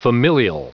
Prononciation du mot familial en anglais (fichier audio)
Prononciation du mot : familial